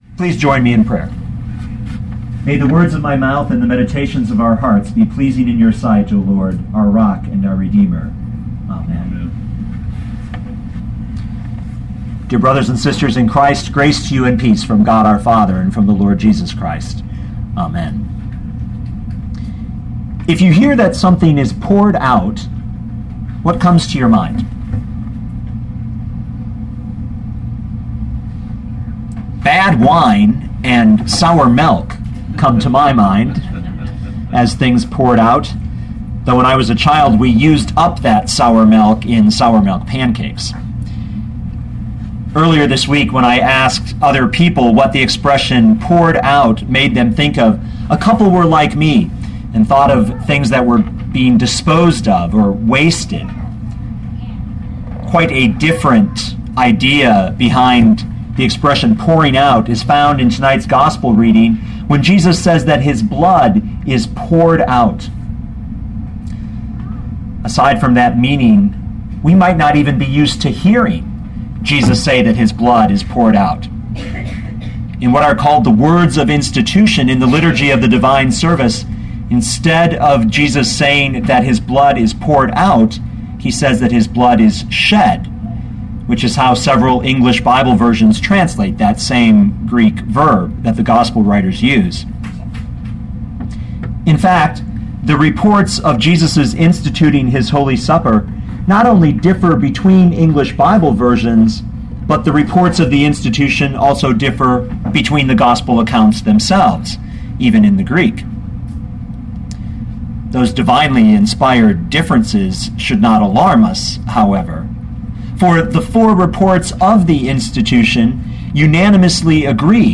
2012 Mark 14:22-24 Listen to the sermon with the player below, or, download the audio.